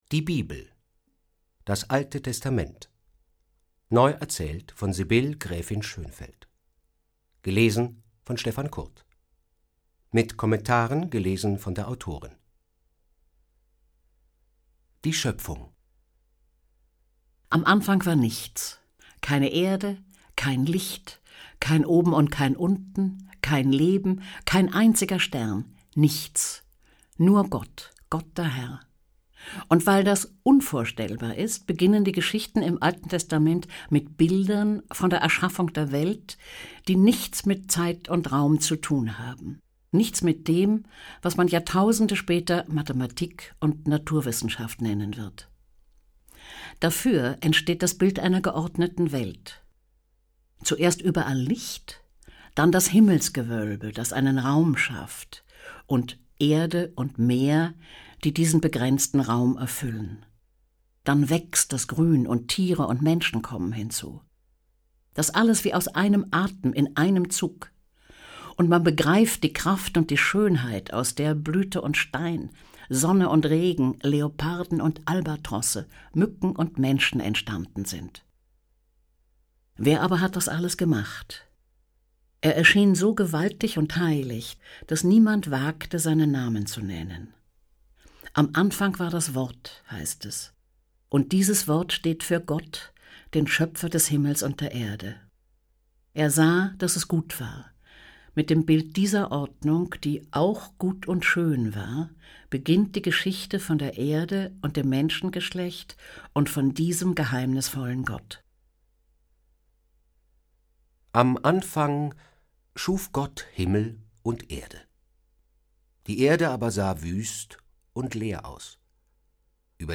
Hörbuch
Stefan Kurt (Sprecher)
Themenwelt Kinder- / Jugendbuch Vorlesebücher / Märchen